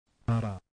[ ɾ ]
U027E Alveolar tap.
U027E_tap_r.mp3